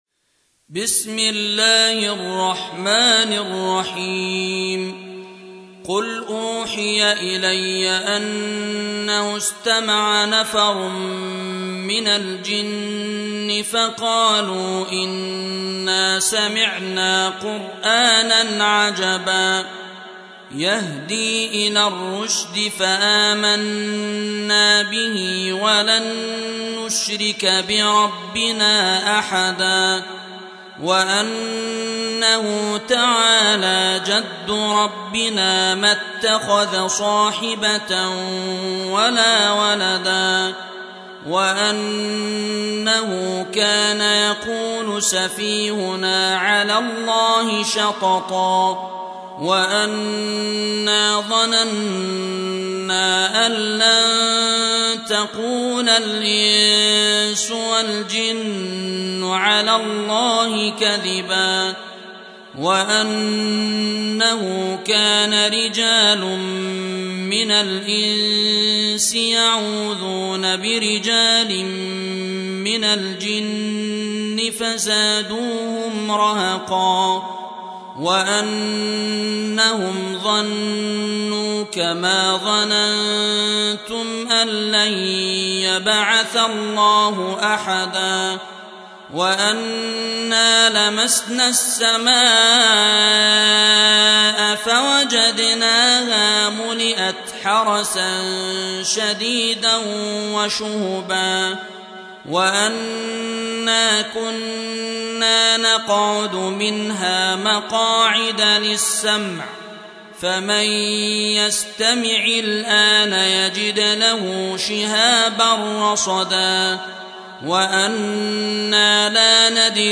Surah Repeating تكرار السورة Download Surah حمّل السورة Reciting Murattalah Audio for 72. Surah Al-Jinn سورة الجن N.B *Surah Includes Al-Basmalah Reciters Sequents تتابع التلاوات Reciters Repeats تكرار التلاوات